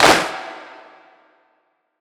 DDW4 GOSPEL CLAP.wav